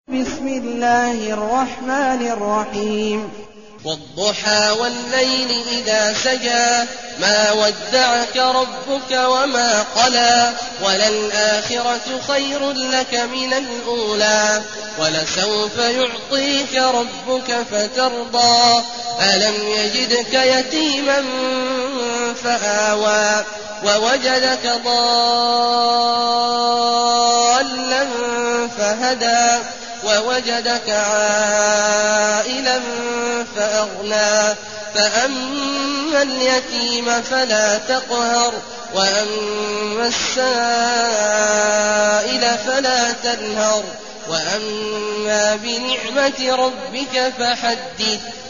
المكان: المسجد النبوي الشيخ: فضيلة الشيخ عبدالله الجهني فضيلة الشيخ عبدالله الجهني الضحى